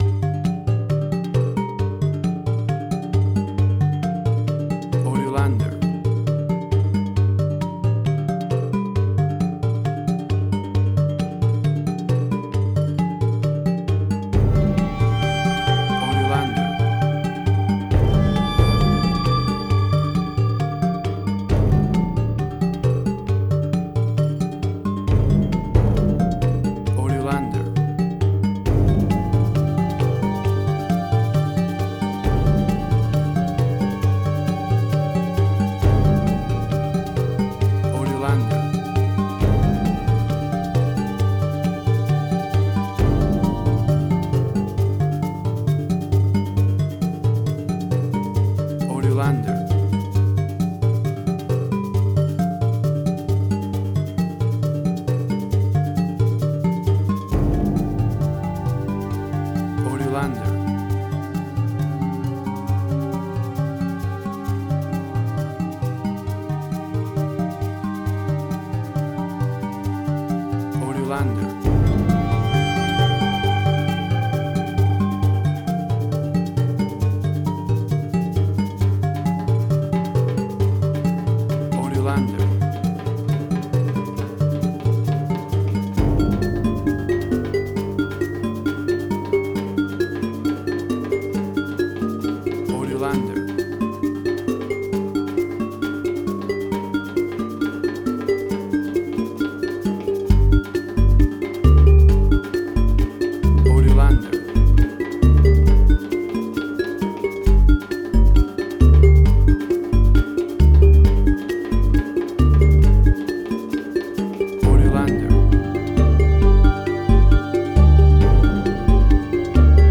Spaghetti Western
Tempo (BPM): 135